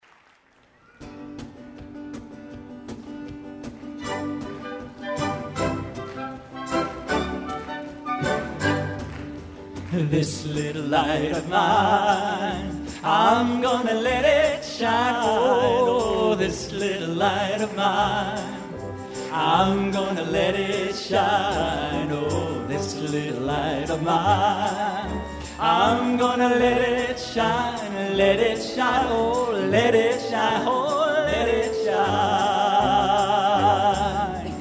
This is a live recording of that magical afternoon.